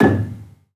Gambang-G#1-f.wav